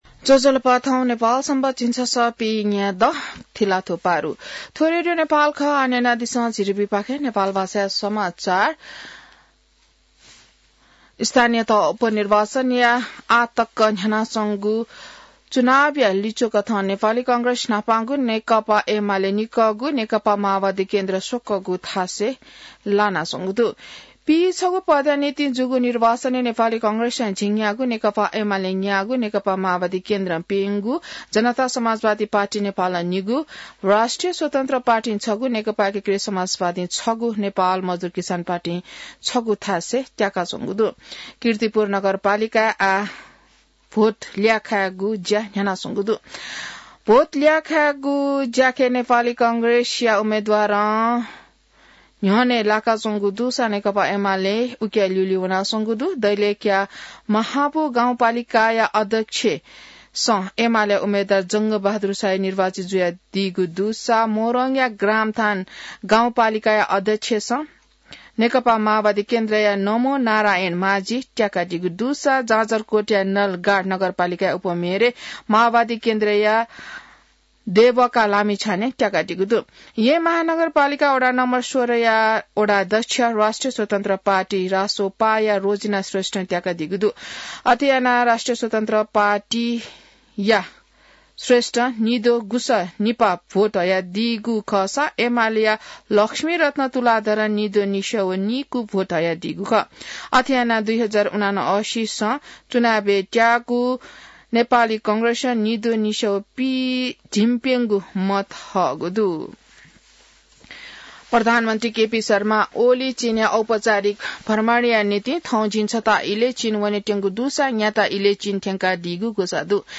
नेपाल भाषामा समाचार : १८ मंसिर , २०८१